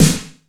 Index of /90_sSampleCDs/Masterbits - Soniq Elements/SPECL FX 9+8/WET SNARES
WET S909  -R 1.wav